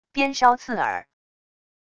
鞭梢刺耳wav音频